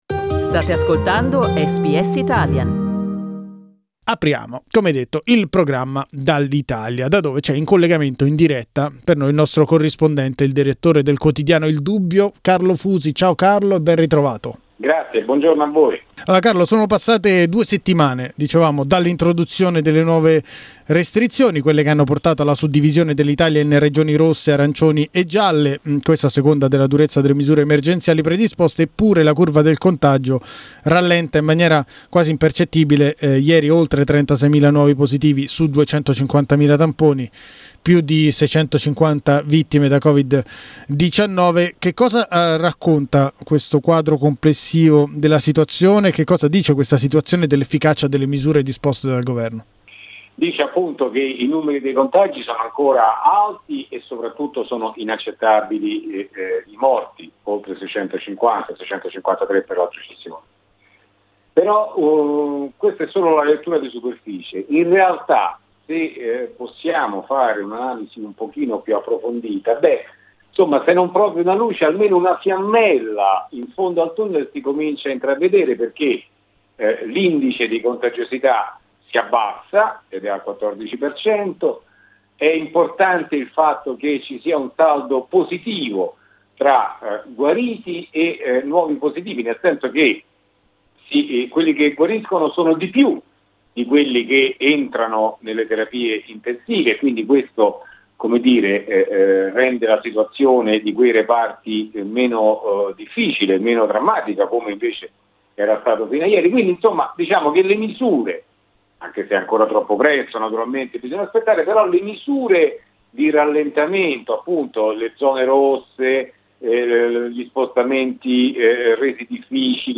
in collegamento con SBS Italian da Roma